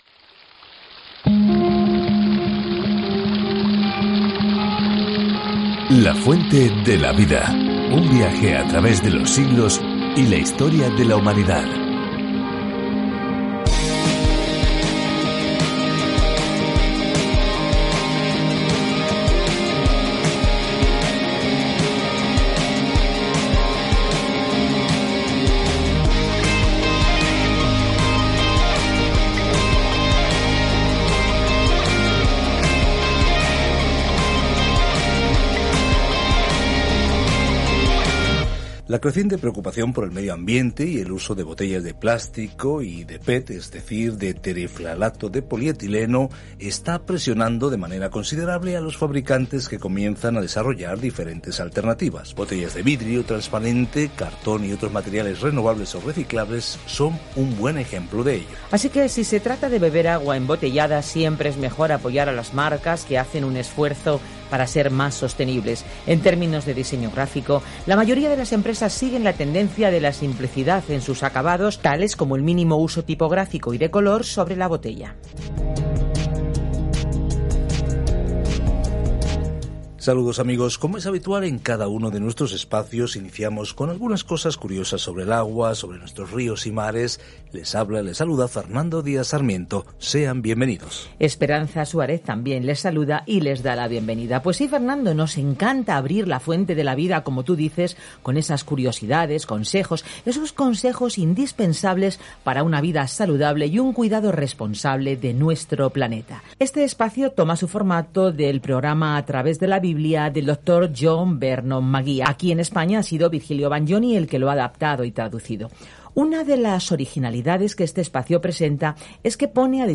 Escritura FILIPENSES 1 Iniciar plan Día 2 Acerca de este Plan Esta nota de “gracias” a los filipenses les brinda una perspectiva gozosa de los tiempos difíciles que atraviesan y los alienta a superarlos juntos con humildad. Viaja diariamente a través de Filipenses mientras escuchas el estudio en audio y lees versículos seleccionados de la palabra de Dios.